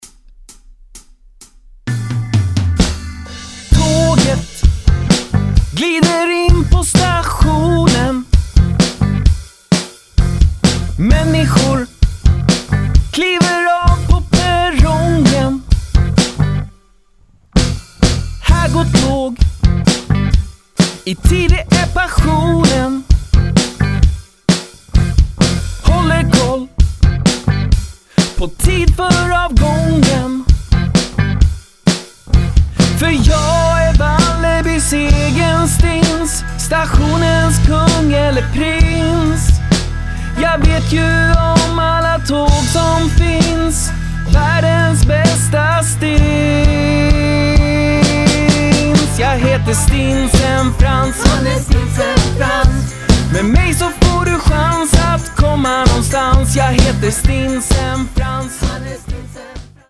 Sångversion